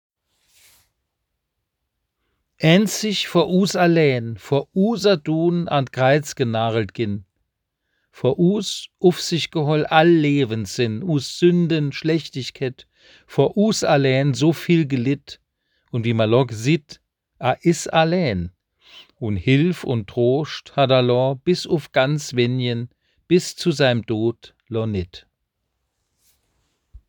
Es enth�lt eine Tonversion der obenstehenden moselfr�nkischen Kreuzwegbetrachtung Sie k�nnen es unter diesem Link abrufen.